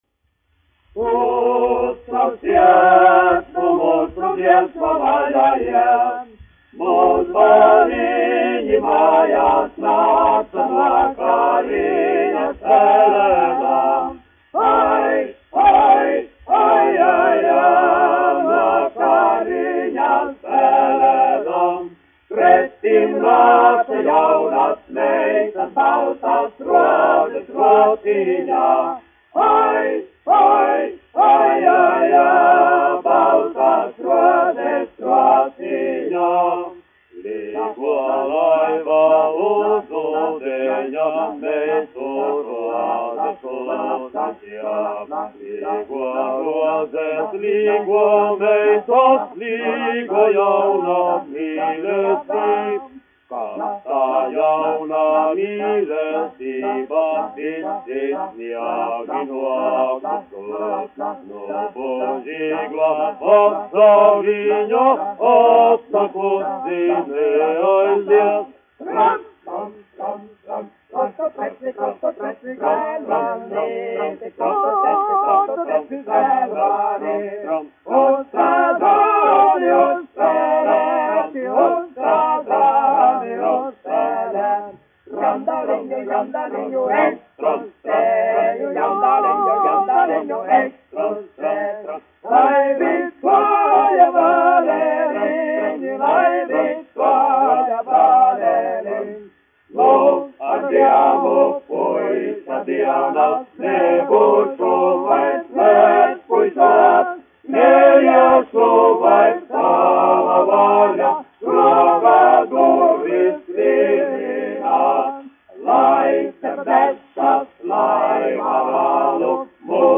1 skpl. : analogs, 78 apgr/min, mono ; 25 cm
Latviešu tautasdziesmu aranžējumi
Populārā mūzika -- Latvija
Vokālie seksteti
Skaņuplate